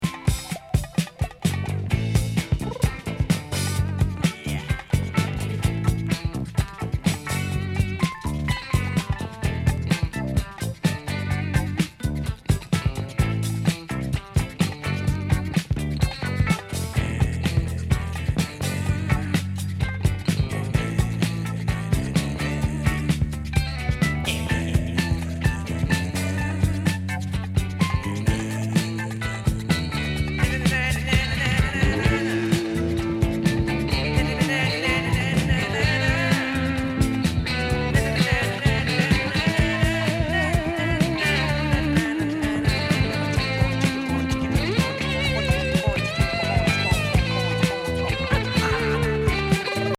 インスト・ファンク・グルーヴ